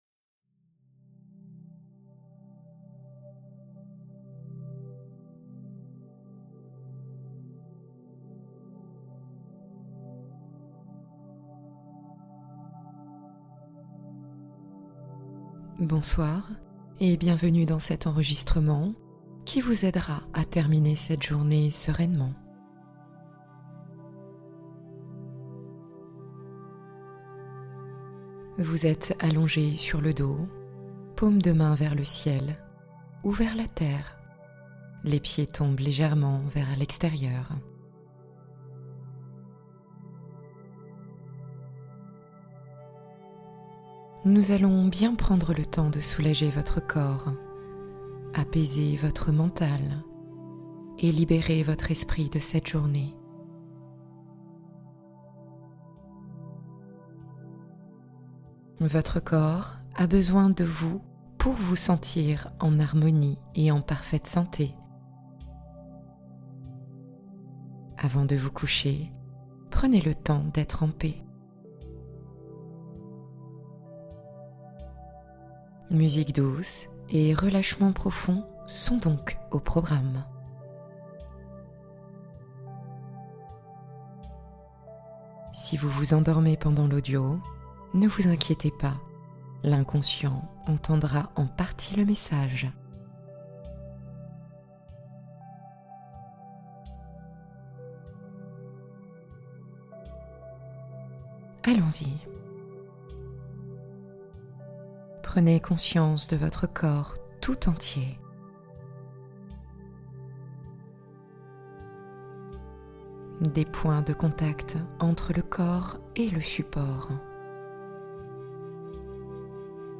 Détente profonde garantie | Méditation du soir pour relâcher toutes les tensions du corps